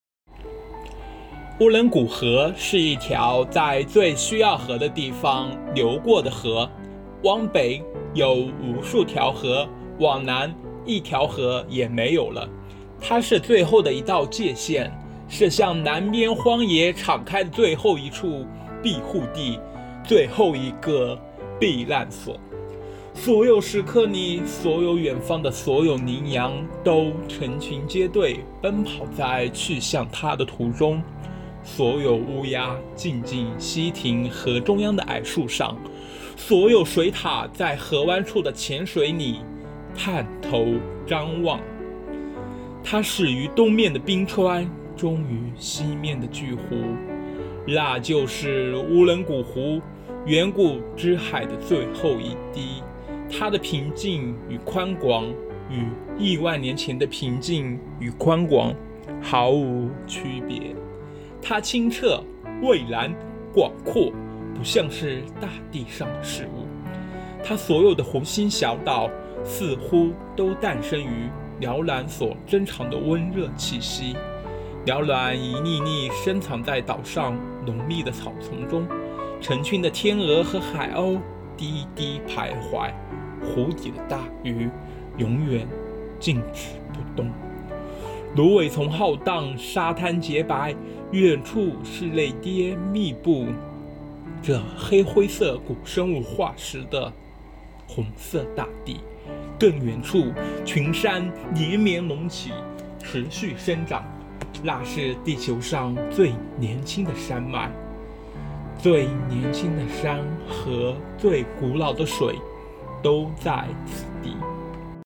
今天，几位同学选取了自己喜欢的段落进行朗诵，与大家分享他们在《遥远的向日葵地》中看到的风景。